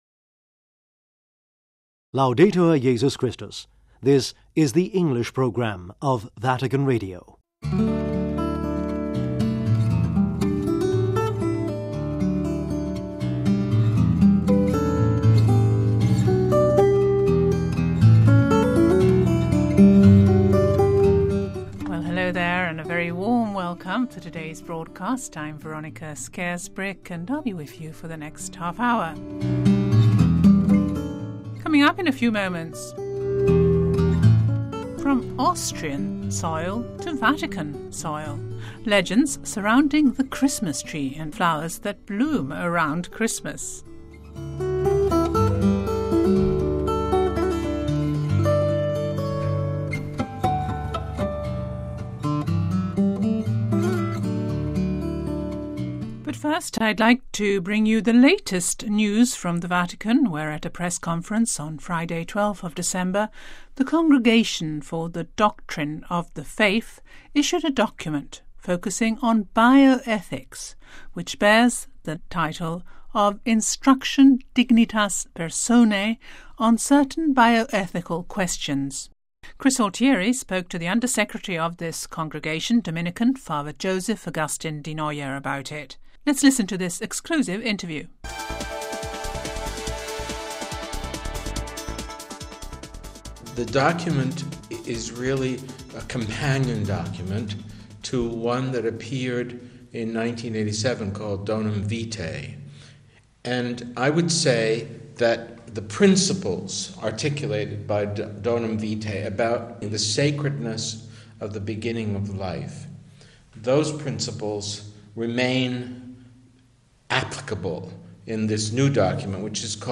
this exclusive interview